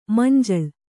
♪ manjaḷ